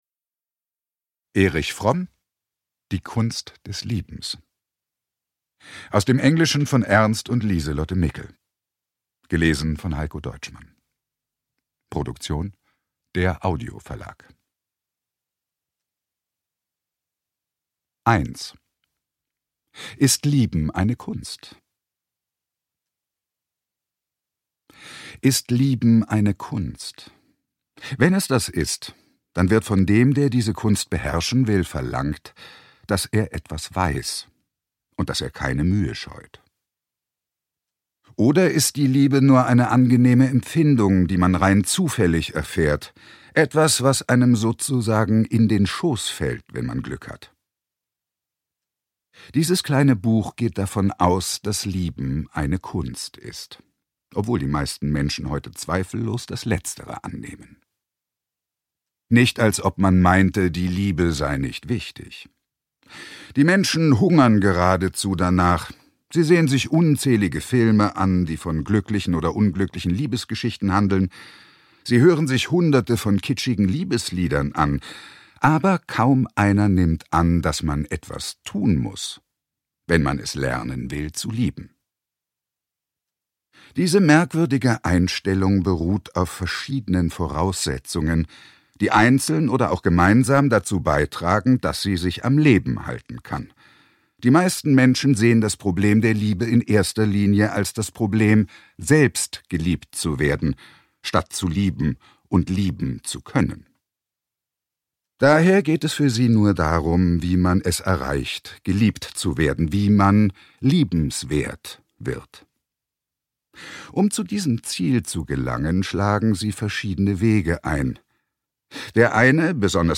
Heikko Deutschmann (Sprecher)
Ungekürzte Lesung mit Ulrich Noethen